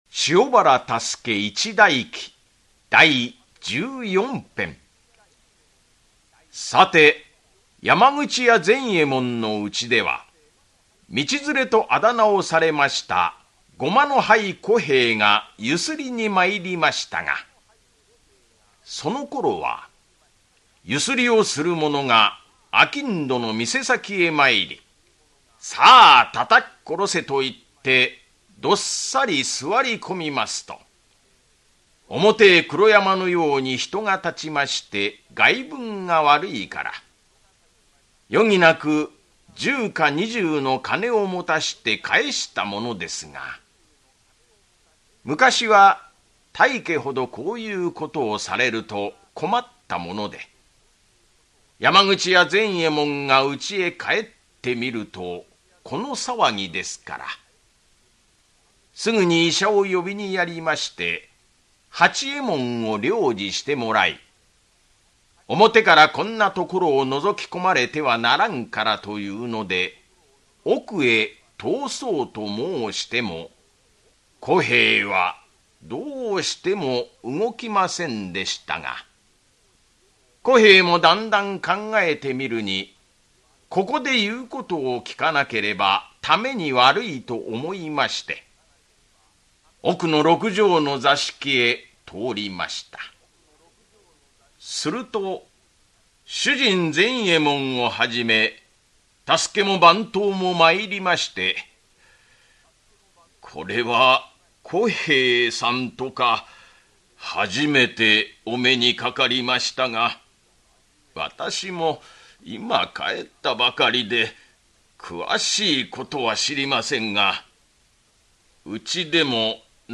[オーディオブック] 塩原多助一代記-第十四・十五編-